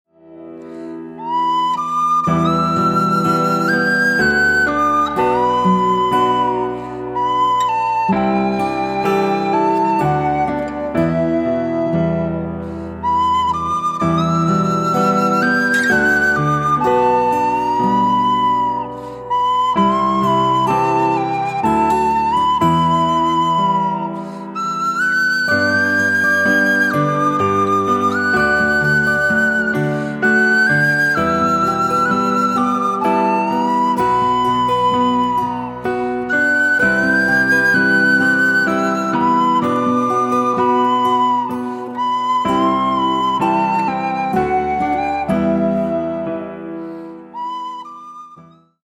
Folk Pop, Irish, Celtic, Worldmusic